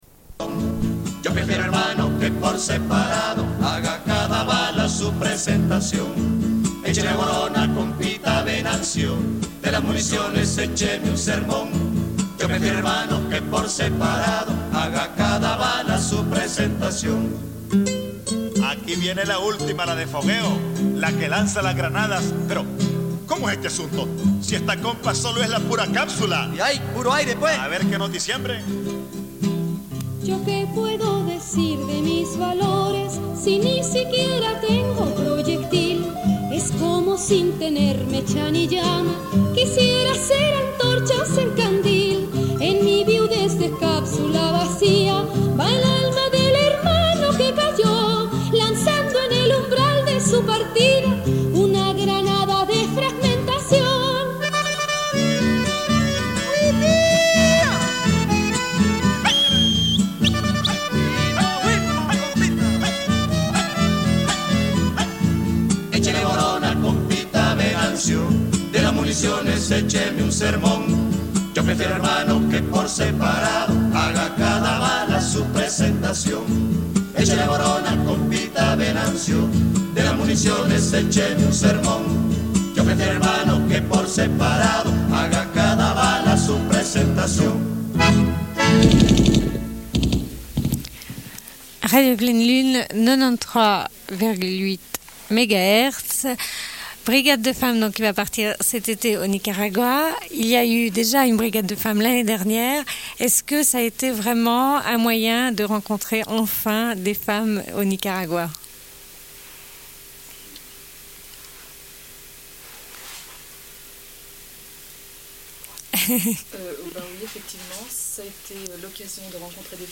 Une cassette audio, face B31:24
00:01:49 // Discussion avec des femmes d'une brigade de jardinières qui ont déjà voyagé au Nicaragua.